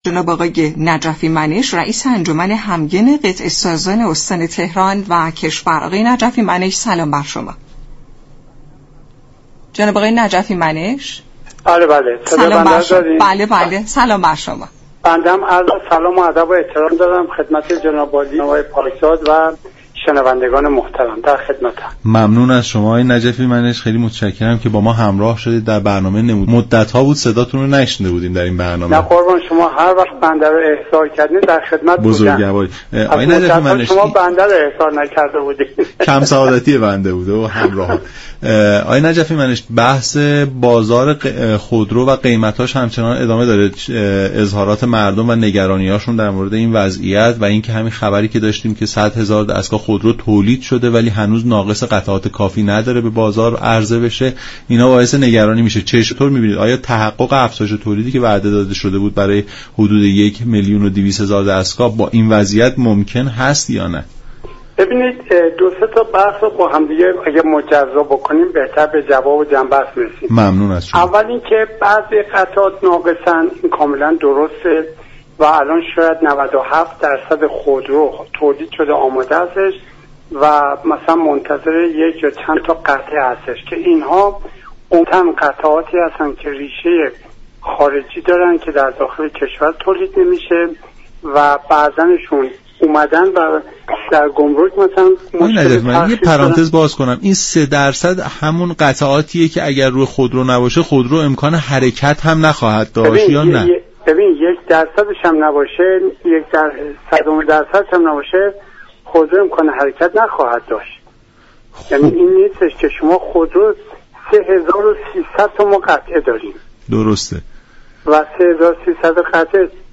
این گفت و گو را در ادامه باهم می شنویم.